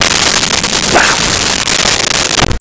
Cougar Snarl Growl 02
Cougar Snarl Growl 02.wav